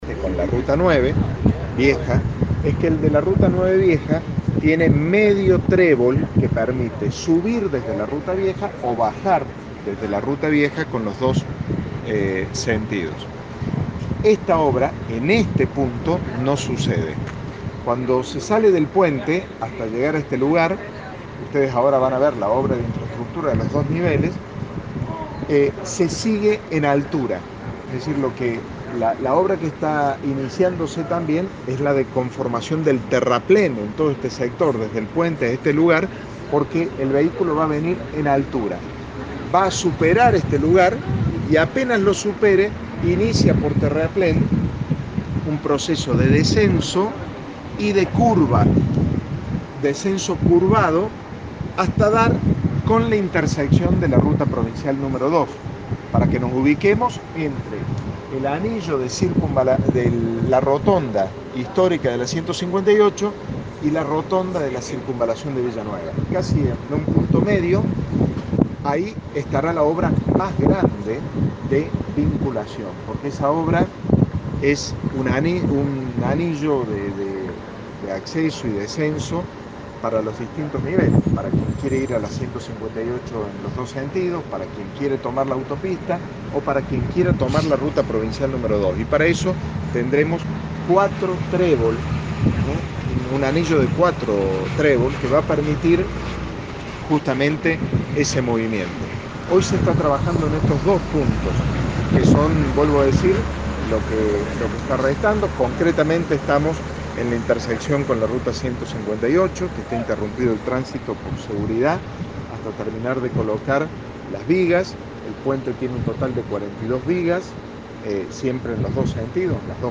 Esta mañana, el intendente Martín Gill y otros integrantes del gabinete municipal junto con responsables de la obra de circunvalación estuvieron en donde se construye el puente sobre la ruta 158 y que unirá el anillo de circunvalación con esa ruta nacional.
La-palabra-de-Martin-Gill-2.mp3